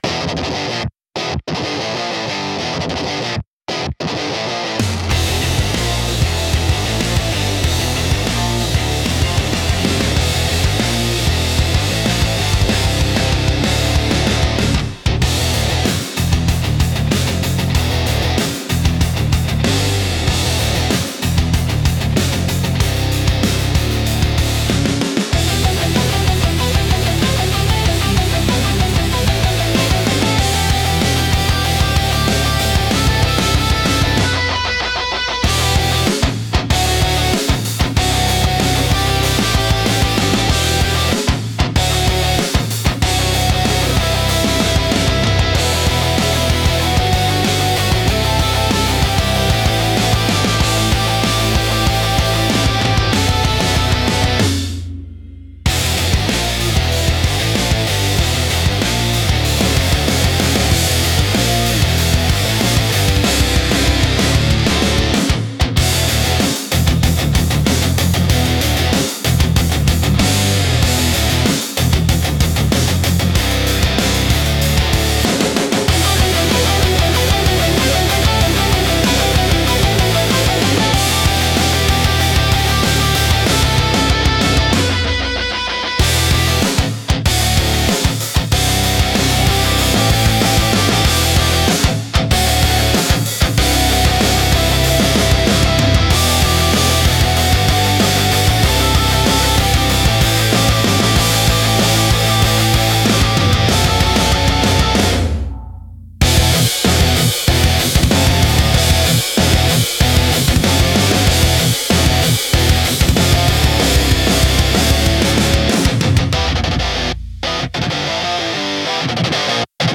Skatepunk Vibes